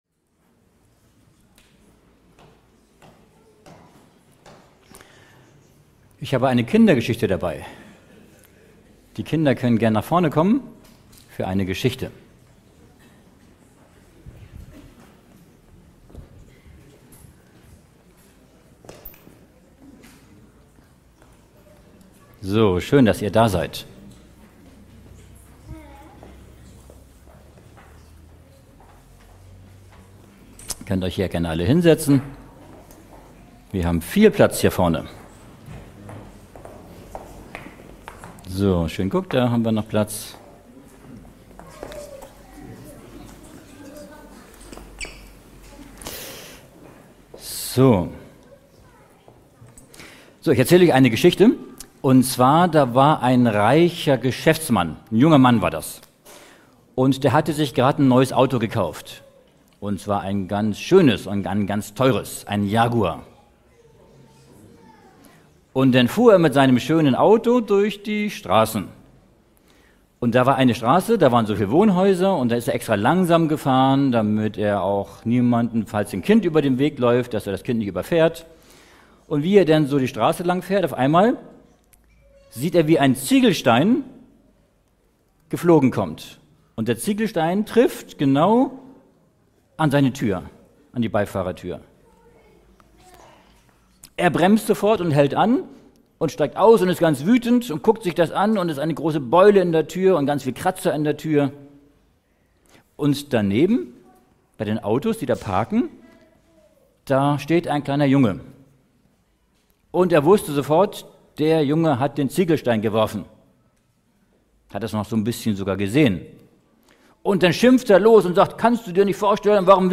In diesem fesselnden Vortrag wird die bedeutende Verbindung zwischen biblischer Prophetie, den angekündigten Plagen und aktuellen Geschehnissen thematisiert. Die Zuhörer werden eingeladen, über die letzte Zeit, den Kampf zwischen Gut und Böse sowie über die Kraft des Glaubens nachzudenken.